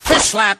音效多为锚和鱼的声音。